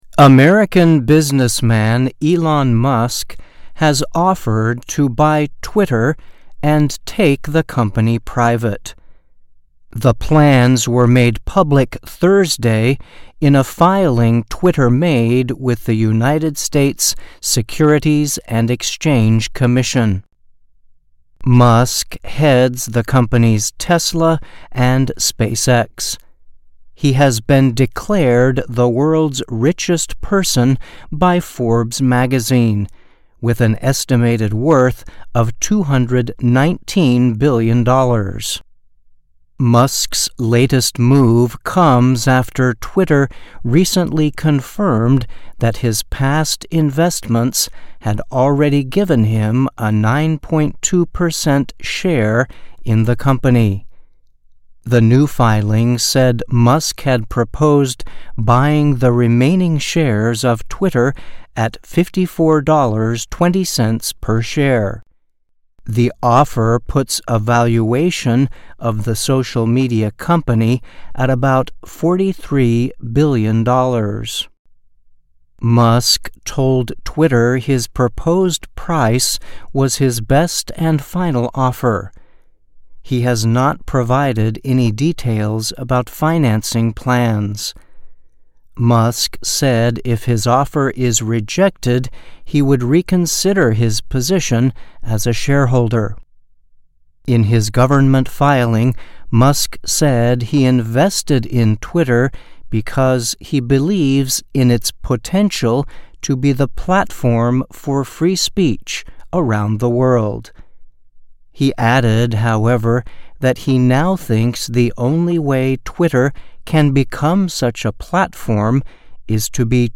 VOA Special English, Technology Report, American Businessman Elon Musk Offers to Buy Twitter